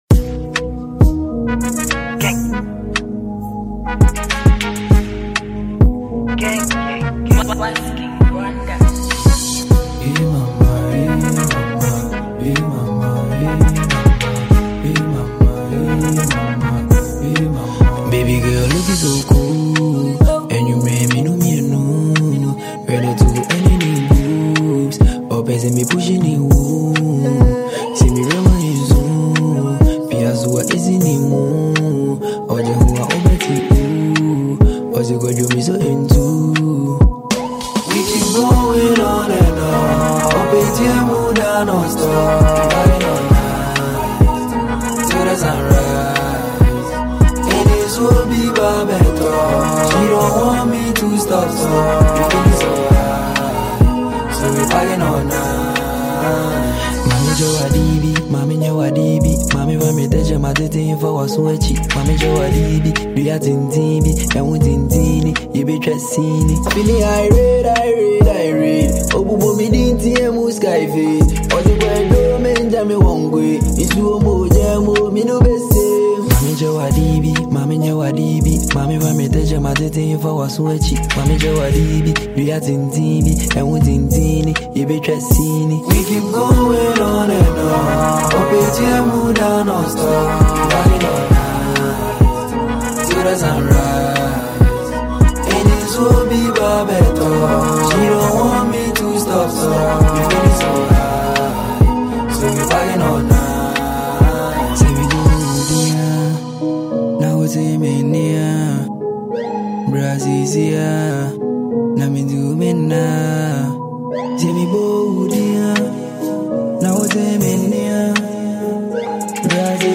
rapper
hard-hitting hip pop track